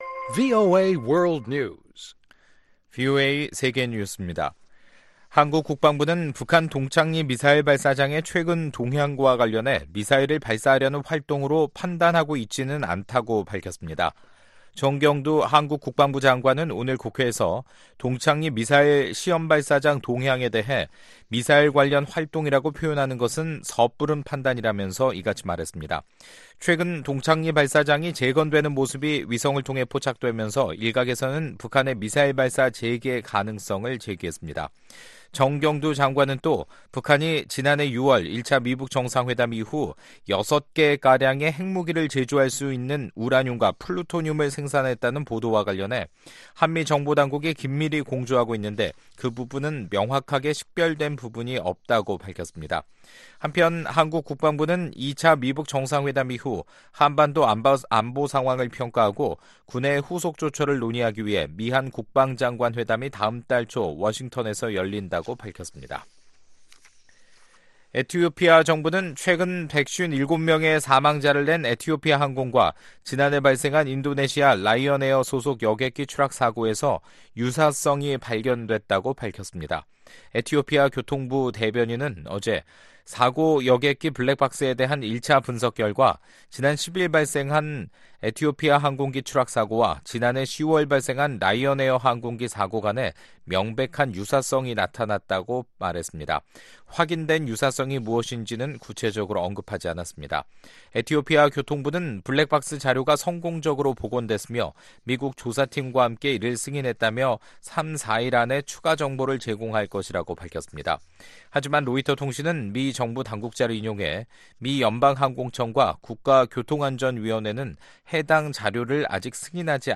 VOA 한국어 간판 뉴스 프로그램 '뉴스 투데이', 2019년 3월 18일 2부 방송입니다. 백악관 고위 관리들은 북한이 핵-미사일 실험을 재개하는 것은 좋은 생각이 아니며, 트럼프 대통령은 협상을 통해 문제 해결을 원한다고 말했습니다. 미 국무부가 미-북 이산가족 상봉을 우선시하도록 요구하는 내용의 법안이 미 하원에서 발의됐습니다.